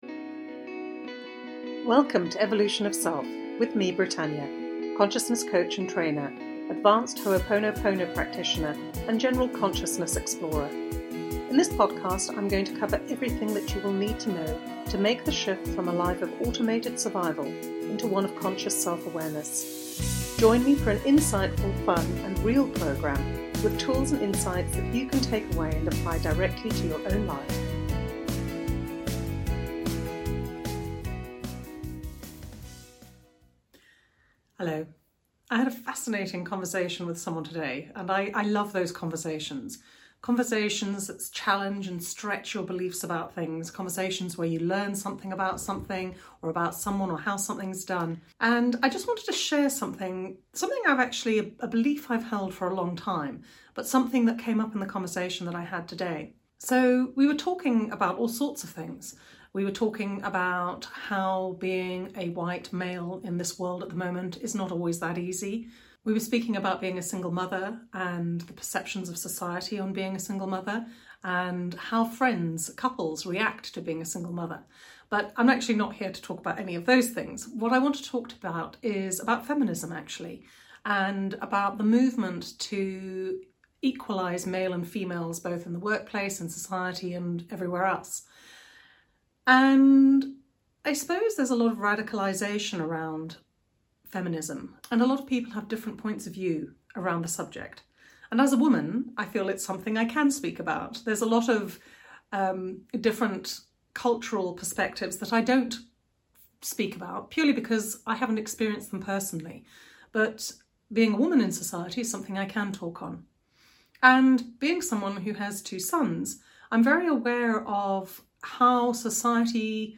I can't talk about some inequalities but I can talk about the inequalities in gender as I am a woman. This week I talk about feminism and what I believe needs to happen. and even if you aren't a woman what I share with you is very pertinent to everyone.